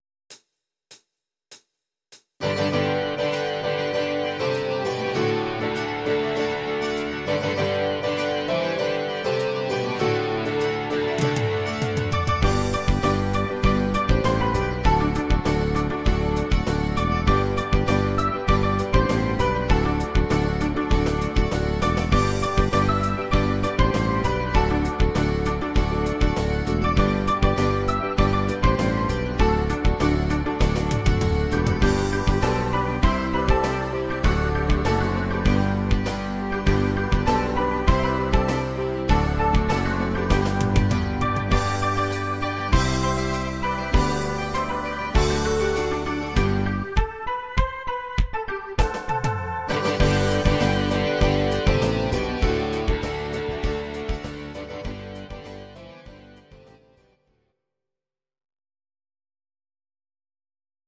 Please note: no vocals and no karaoke included.
Your-Mix: Rock (2958)